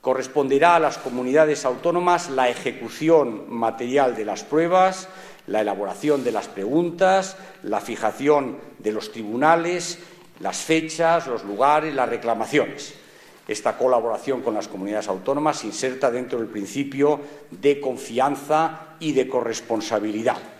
Declaraciones del ministro 1 Audio